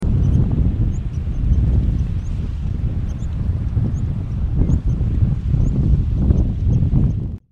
Dormilona Cara Negra (Muscisaxicola maclovianus)
Nombre en inglés: Dark-faced Ground Tyrant
Fase de la vida: Adulto
Localidad o área protegida: Puerto Madryn
Condición: Silvestre
Certeza: Observada, Vocalización Grabada